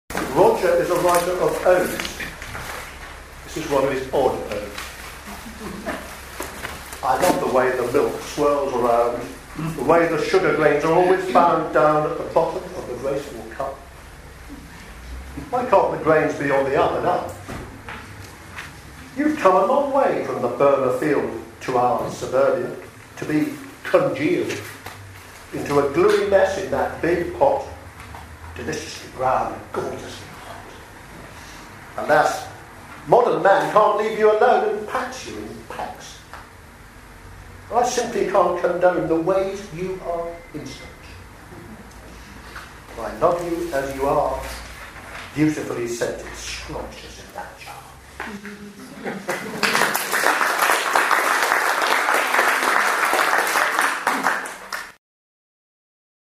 Sounds Like then then put on a launch concert at the Working Men's College on 19 February 2011.